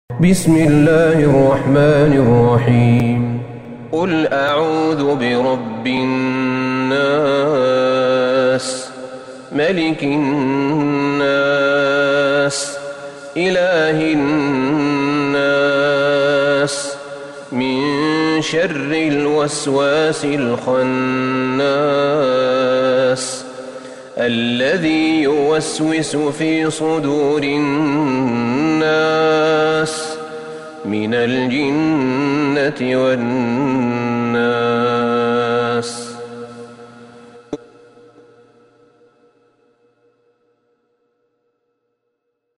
سورة الناس Surat An-Nas > مصحف الشيخ أحمد بن طالب بن حميد من الحرم النبوي > المصحف - تلاوات الحرمين